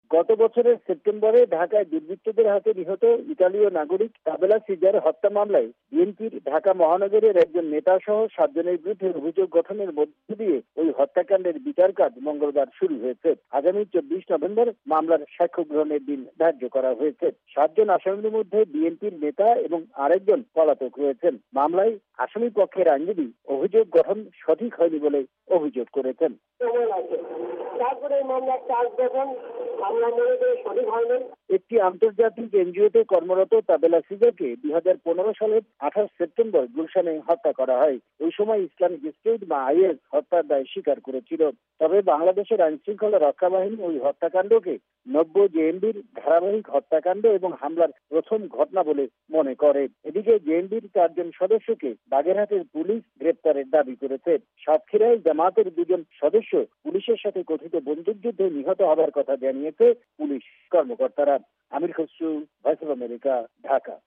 রিপোর্ট (মামলা)